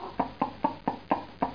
1 channel
huhn1.mp3